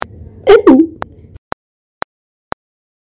POLIWHIRL.mp3